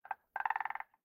sounds / mob / frog / idle2.ogg